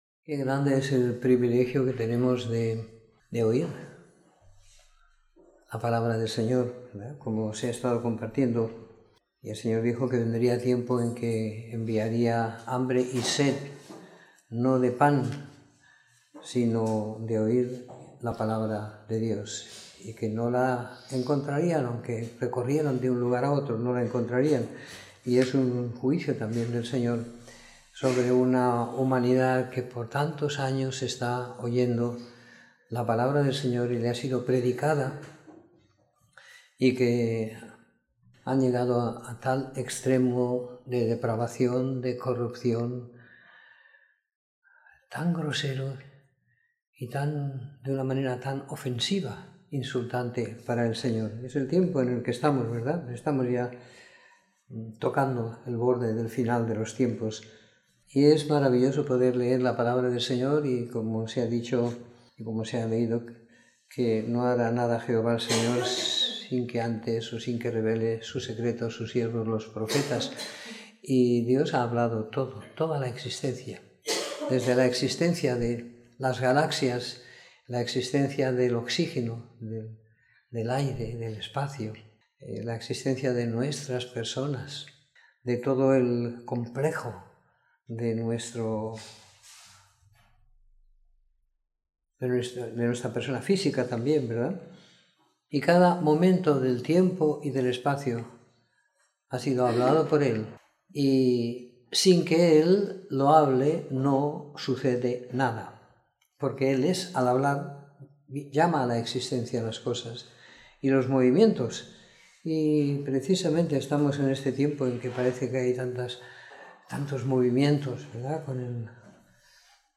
Comentario en los libros de Joel 1 a Jonás 4 y 2ª Pedro del 1 al 3 siguiendo la lectura programada para cada semana del año que tenemos en la congregación en Sant Pere de Ribes.